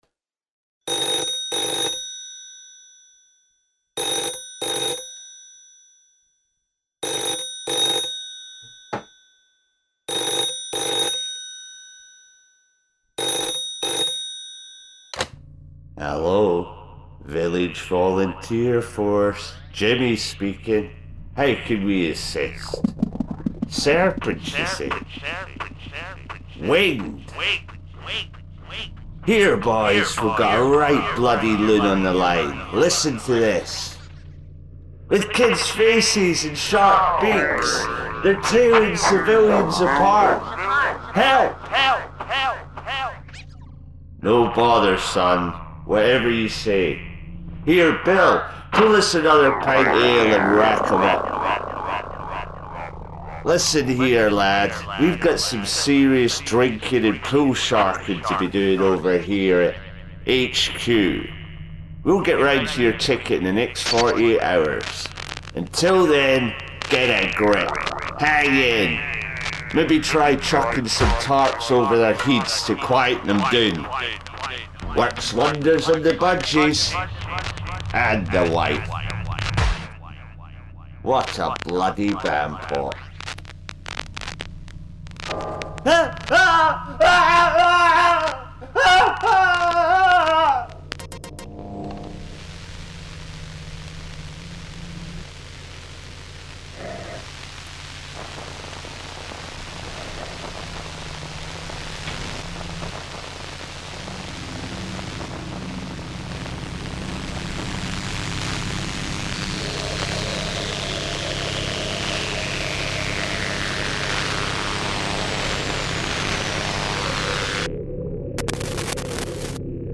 Suggestive Drones and Electro-Acoustic Night Terrors...